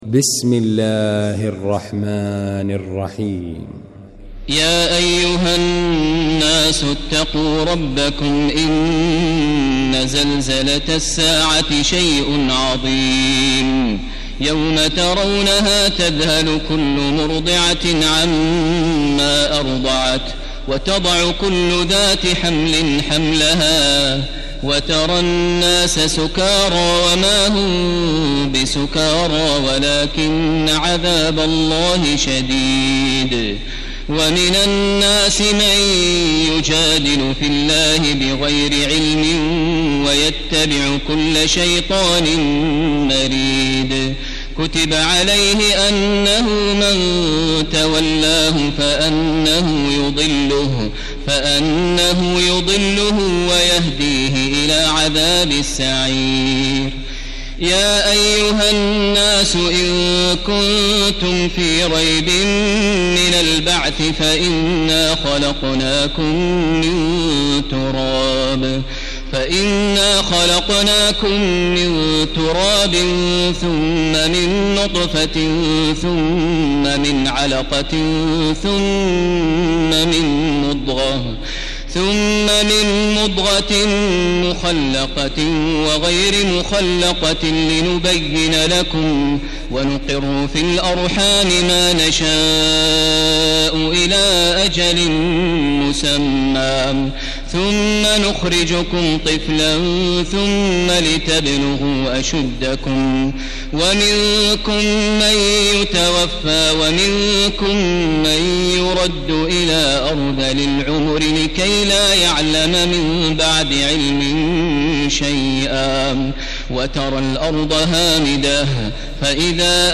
المكان: المسجد الحرام الشيخ: فضيلة الشيخ عبدالله الجهني فضيلة الشيخ عبدالله الجهني الحج